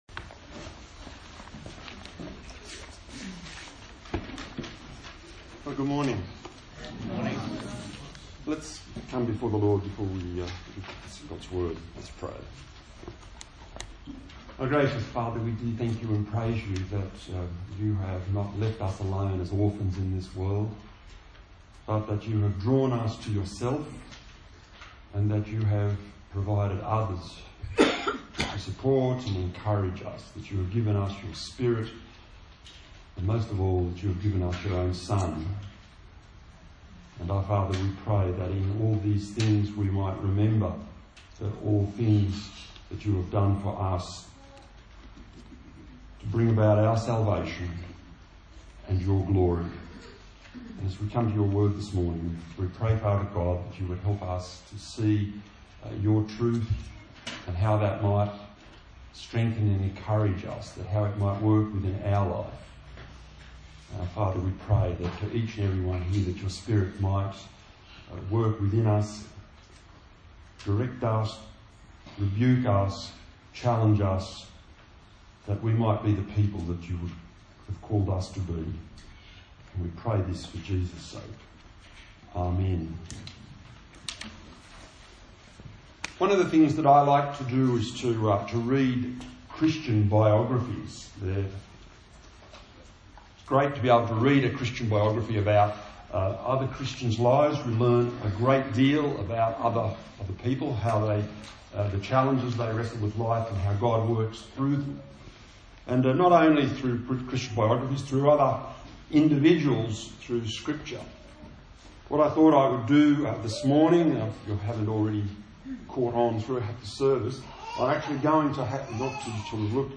The Man of Faith Preacher
Service Type: Sunday Morning